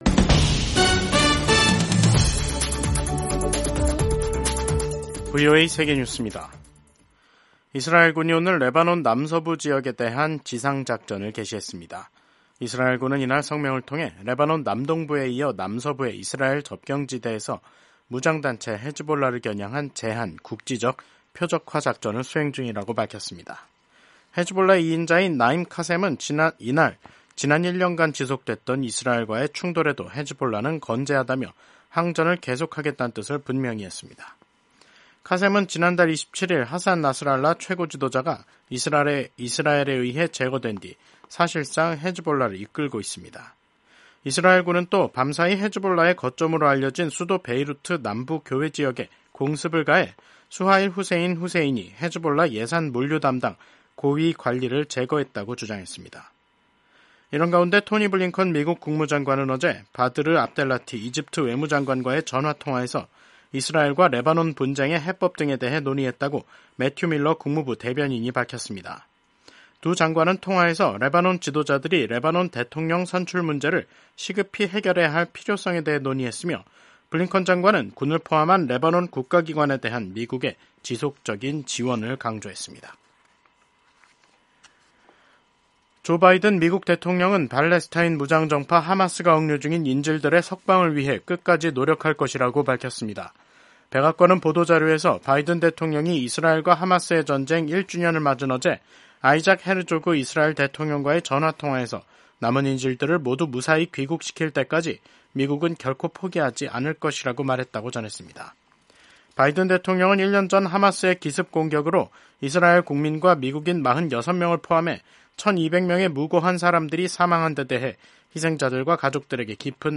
세계 뉴스와 함께 미국의 모든 것을 소개하는 '생방송 여기는 워싱턴입니다', 2024년 10월 8일 저녁 방송입니다. 팔레스타인 가자지구 전쟁이 1주년을 맞은 가운데 이스라엘군이 레바논에서 새로운 지상 작전을 시작했습니다. 미국 민주당 대선 후보인 카멀라 해리스 부통령이 방송 회견에서 각종 현안에 대한 견해를 밝혔습니다. 타이완 총통이 중국은 타이완의 조국이 될 수 없다고 말하며 타이완이 독립 국가라는 점을 강조했습니다.